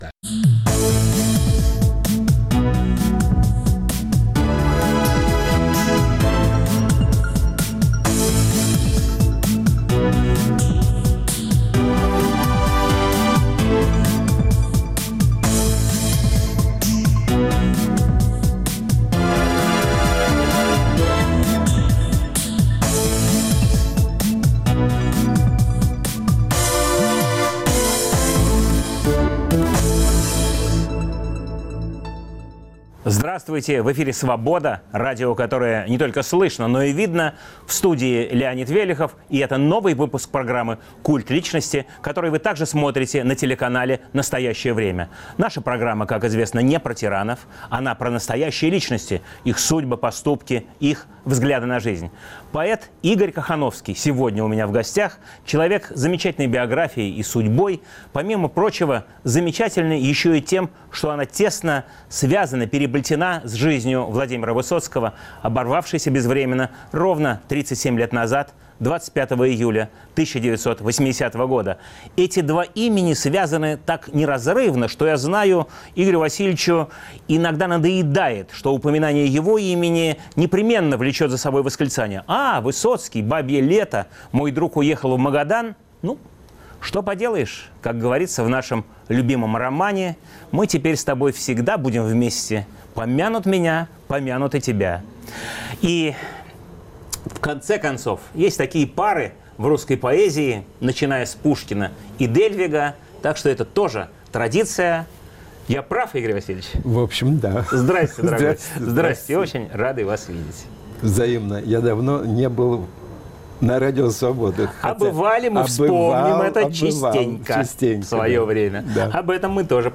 25 июля - 37-я годовщина смерти Владимира Высоцкого. В студии друг его детства и юности, поэт Игорь Кохановский.